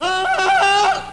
Ayaaaah Sound Effect
ayaaaah.mp3